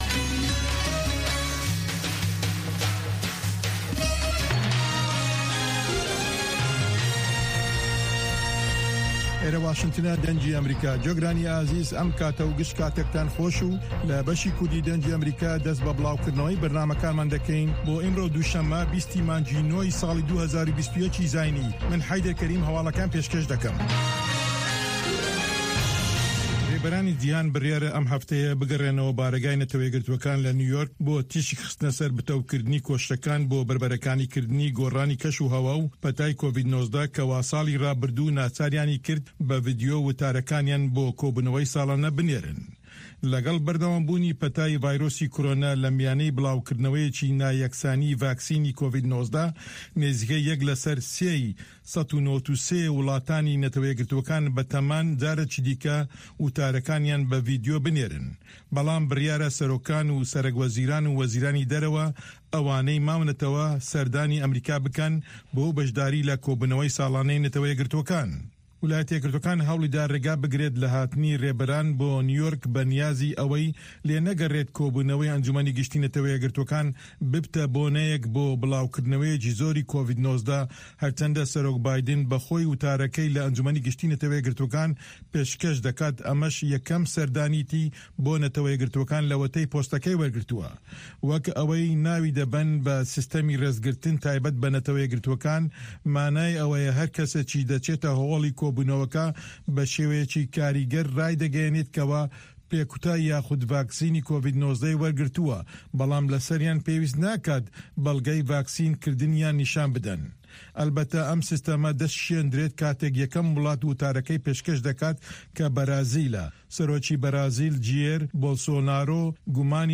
هەواڵەکانی 1 ی شەو